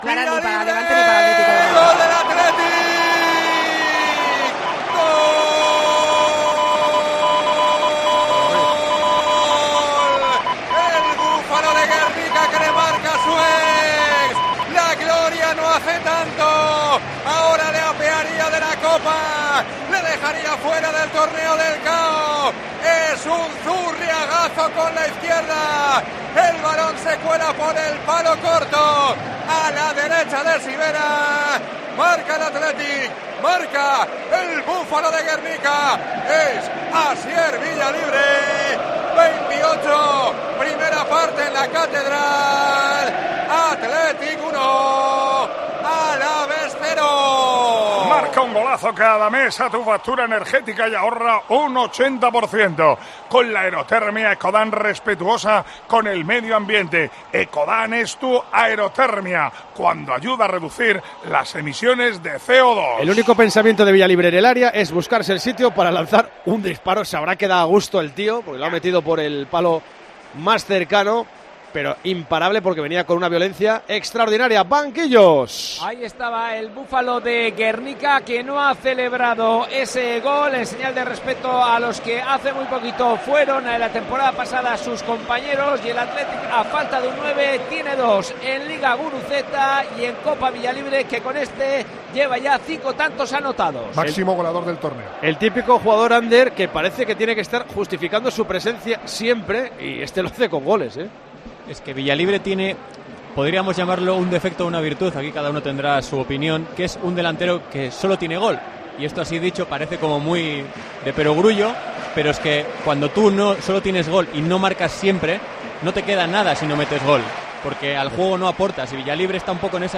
narra en Tiempo de Juego el primer gol de Asier Villalibre